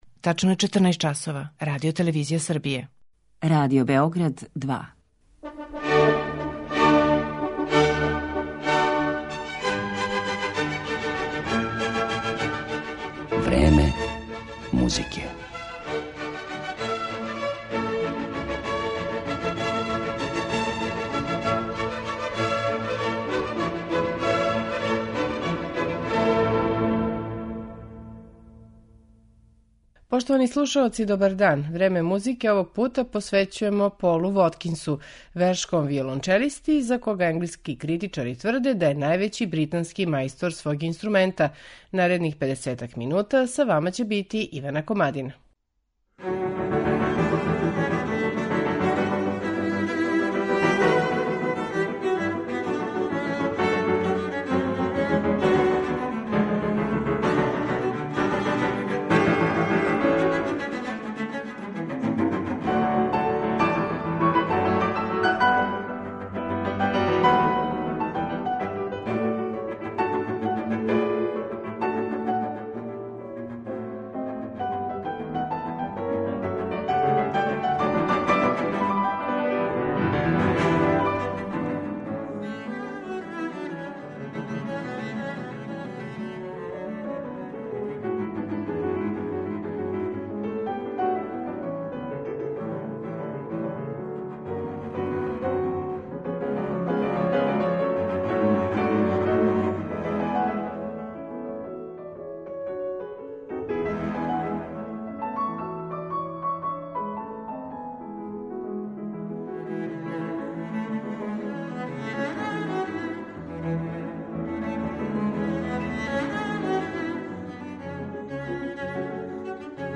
виолончелиста